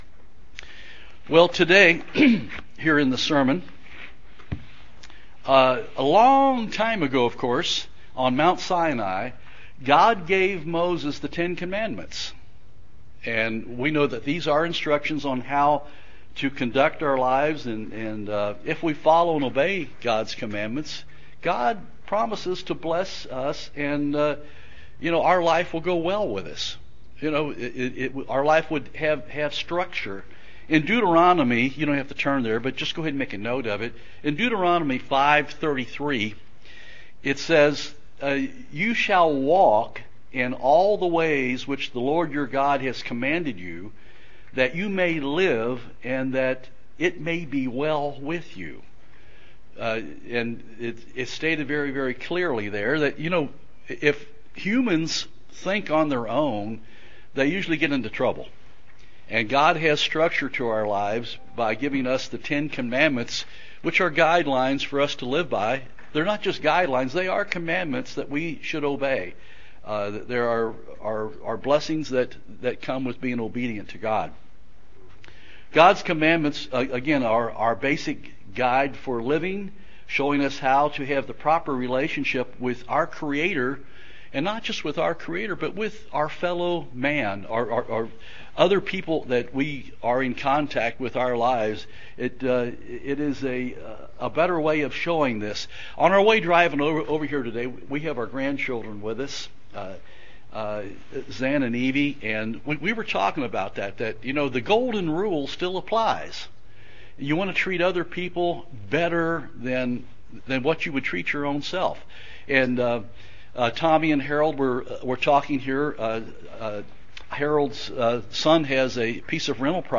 Given in Jackson, TN
UCG Sermon Studying the bible?